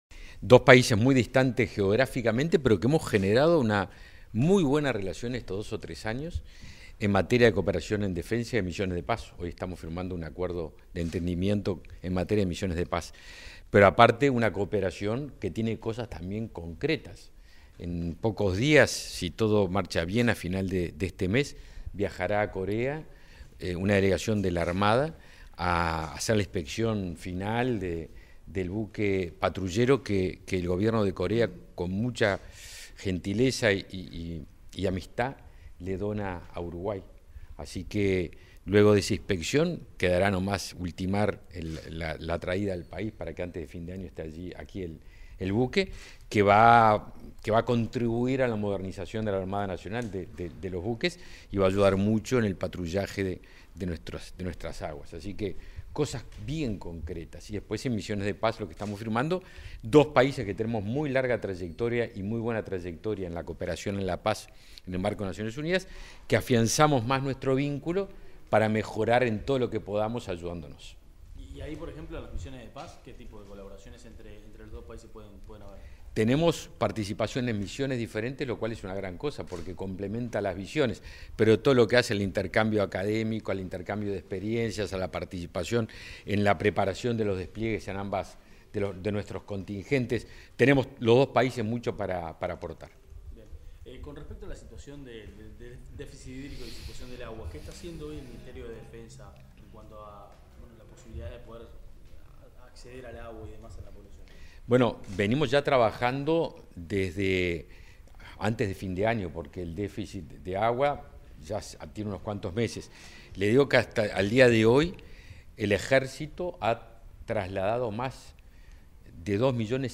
Declaraciones del ministro de Defensa Nacional, Javier García
Declaraciones del ministro de Defensa Nacional, Javier García 16/05/2023 Compartir Facebook X Copiar enlace WhatsApp LinkedIn Tras la firma de un acuerdo en materia de misiones de paz con la República de Corea, el ministro de Defensa Nacional, Javier García, realizó declaraciones a la prensa.